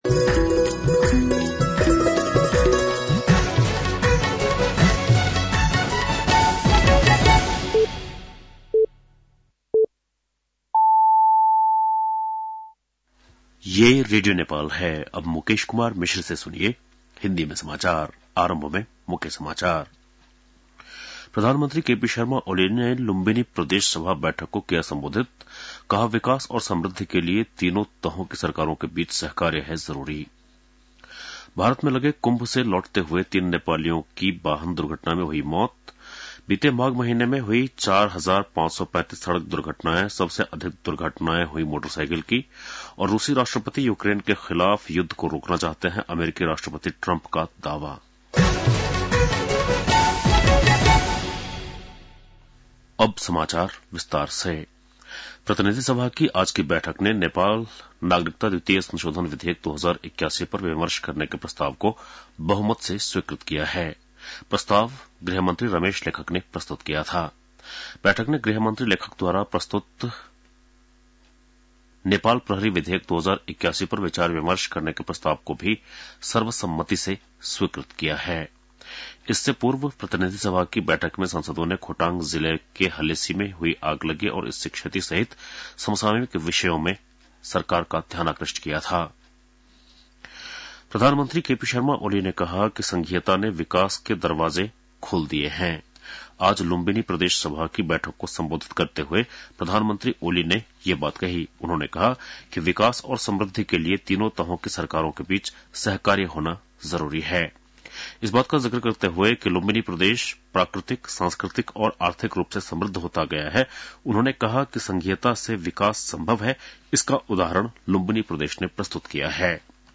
बेलुकी १० बजेको हिन्दी समाचार : ६ फागुन , २०८१